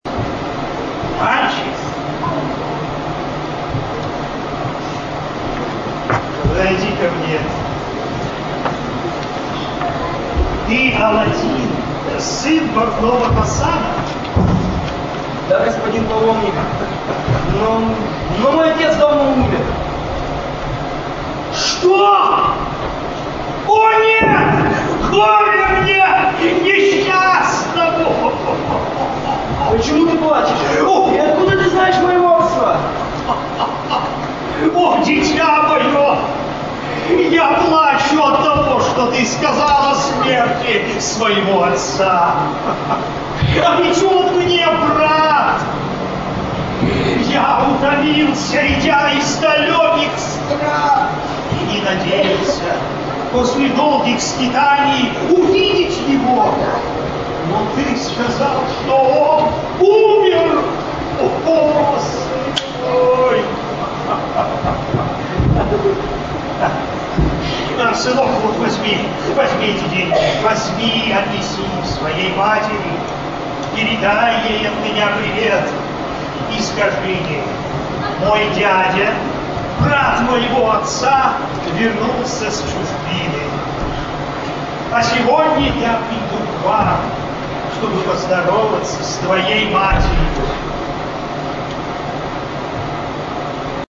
23 марта 2009 года спектакль "Аладдин и его волшебная лампа" был показан на фестивале во втором составе.
И сразу же извиняюсь за качество звука. В зале над главным проходом размещен видео проектор, который и производит такой шум.
Такой эффект дает наложенная на запись компрессия.